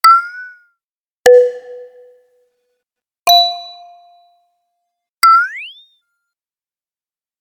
05706 dinging interface sounds
button choose click counds ding interface menu select sound effect free sound royalty free Sound Effects